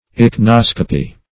Meaning of ichnoscopy. ichnoscopy synonyms, pronunciation, spelling and more from Free Dictionary.
ichnoscopy.mp3